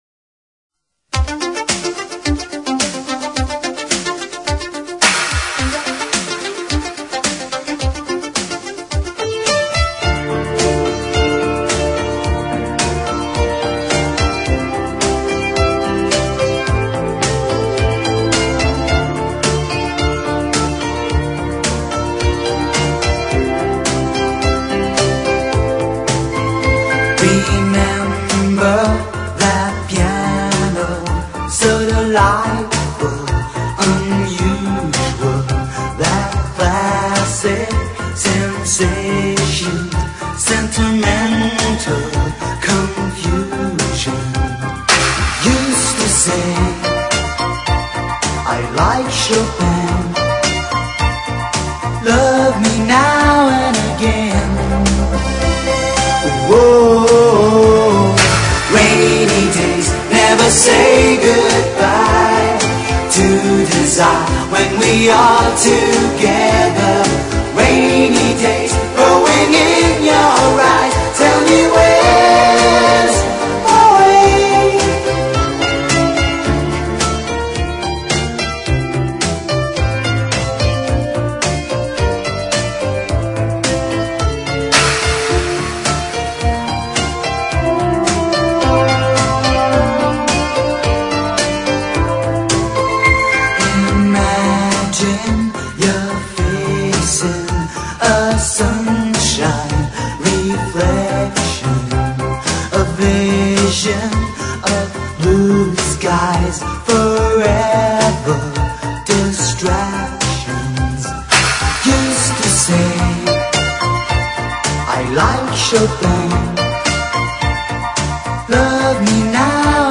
Flashback Para Ouvir: Clik na Musica.